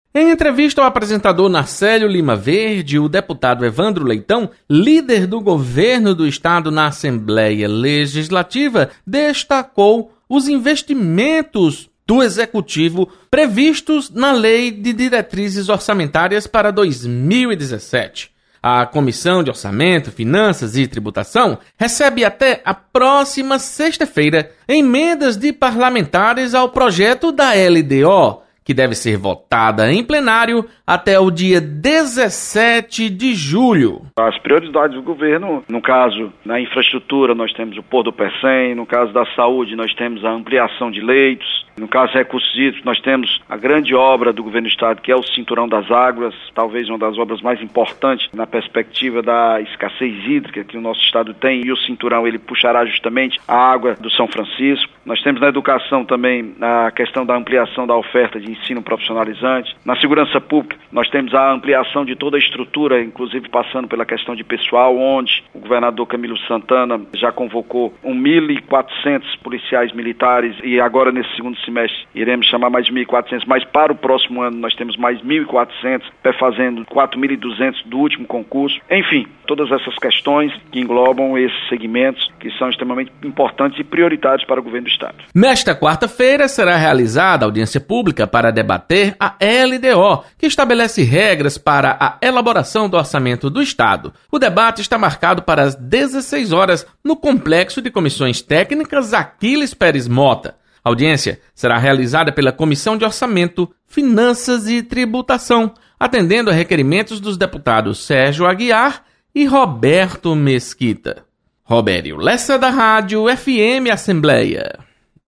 Deputado Evandro Leitão destaca prioridades dos investimentos públicos previstos na LDO.